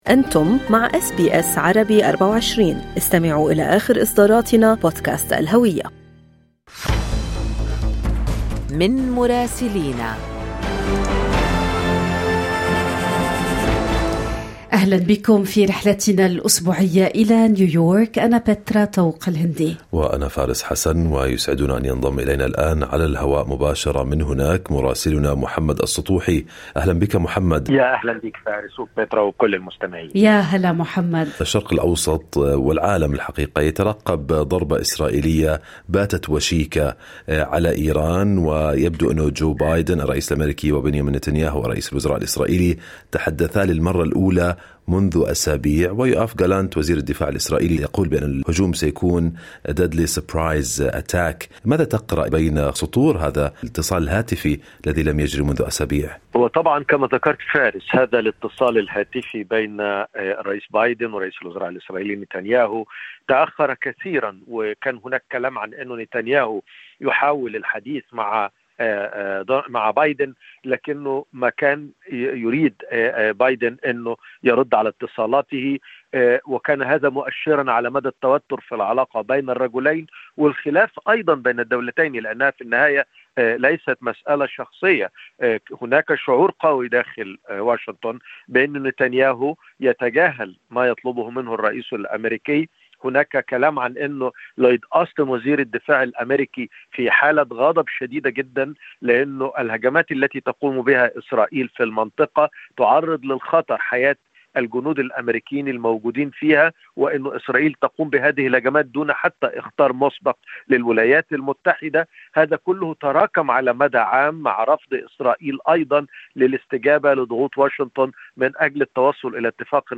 تحدث جو بايدن وبنيامين نتنياهو للمرة الأولى منذ أسابيع، وسط توقعات بهجوم إسرائيلي وشيك على إيران، وهو ما حذر منه يوآف غالانت بأنه سيكون "قاتلاً ودقيقاً ومفاجئاً". التفاصيل مع مراسلنا في نيويورك